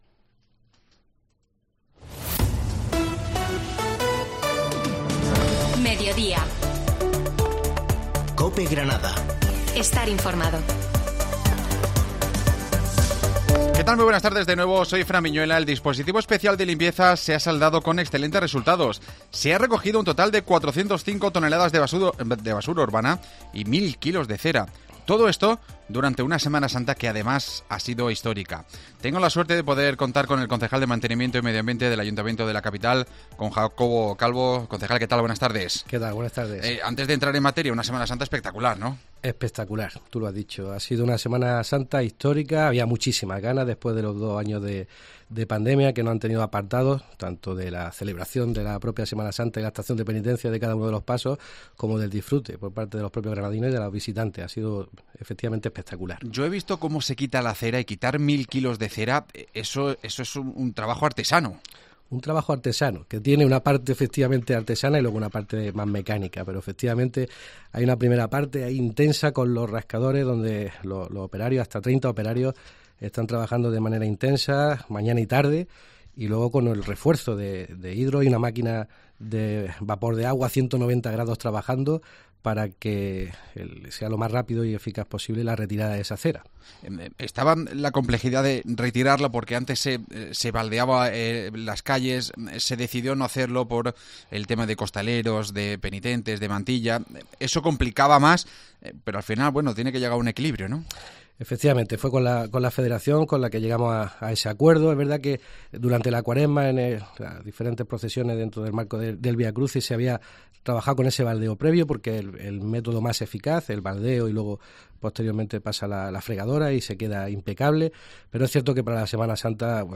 AUDIO: Hablamos con el concejal de mantenimiento y medio ambiente, Jacobo Calvo